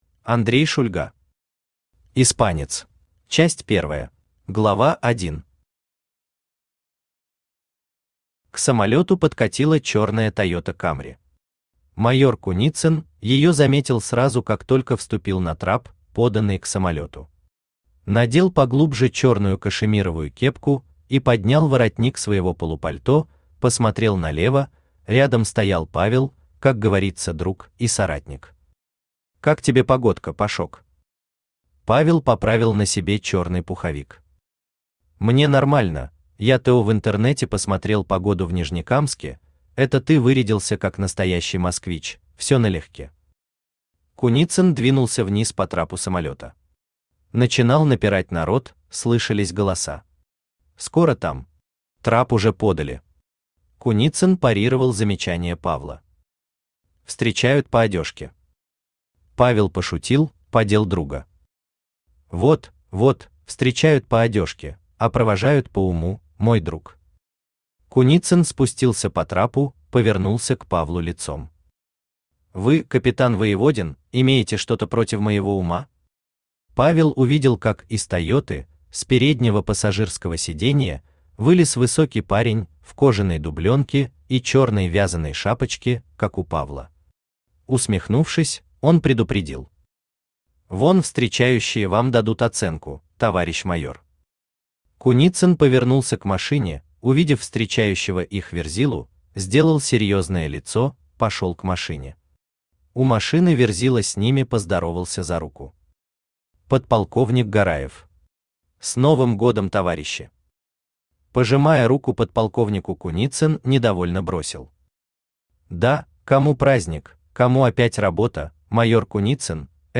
Аудиокнига Испанец | Библиотека аудиокниг
Aудиокнига Испанец Автор А. В. Шульга Читает аудиокнигу Авточтец ЛитРес.